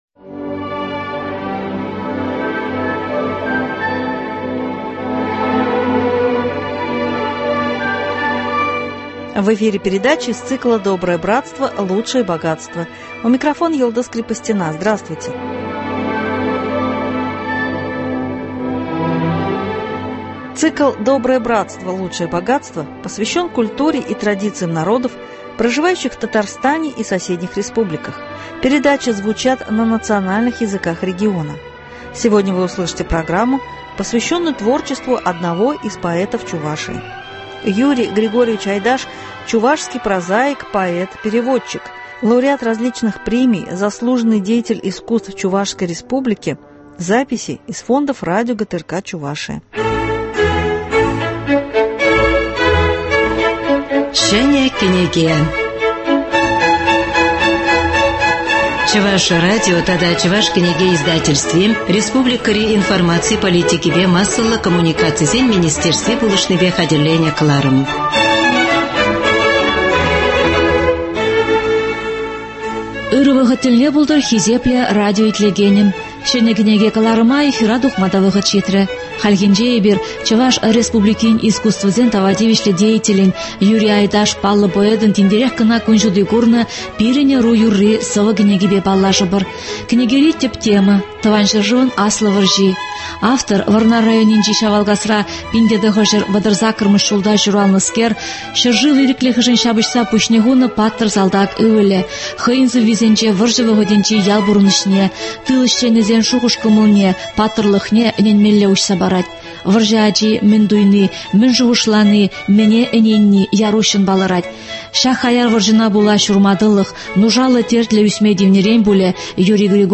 Лауреат различных премий, заслуженный деятель искусств Чувашской Республики .Записи из фондов радио ГТРК Чувашия.